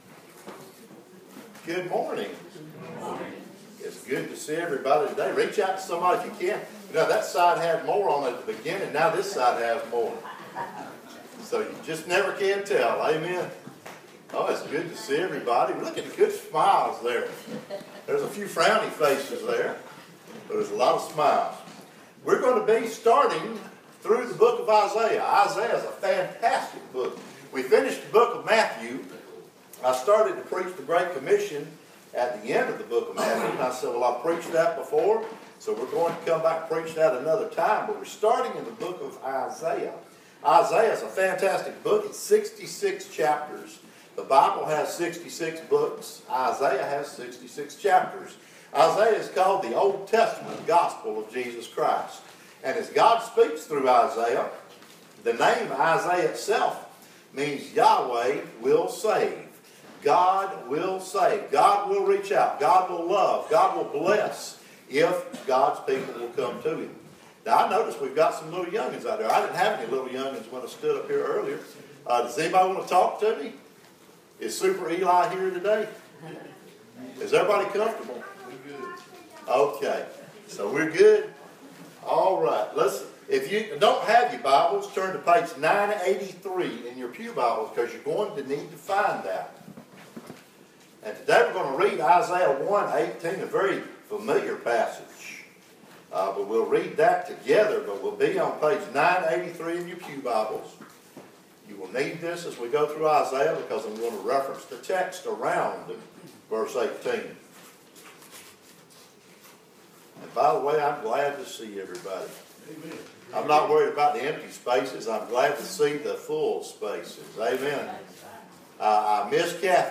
Sermon Notes (Audio at end of text): The message of Isaiah is seen in his very name.